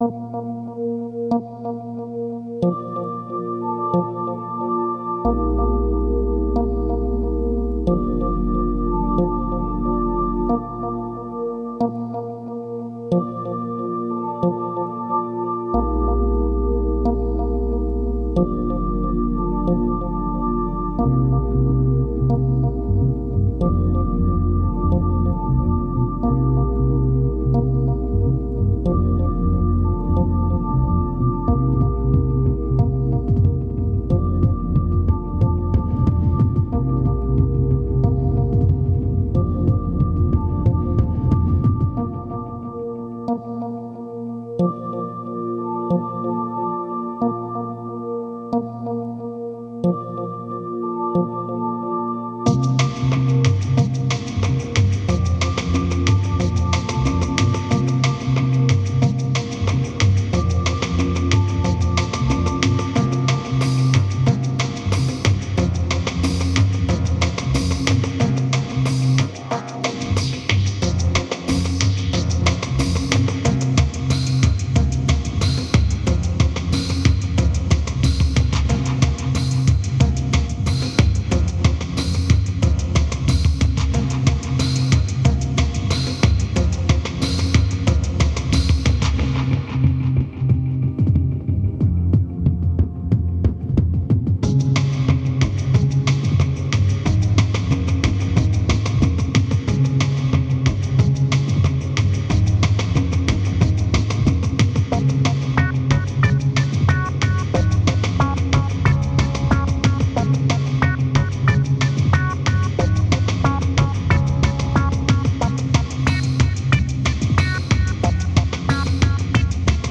2815📈 - 80%🤔 - 183BPM🔊 - 2009-09-03📅 - 684🌟